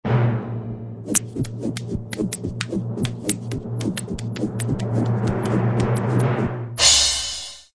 AA_heal_juggle.ogg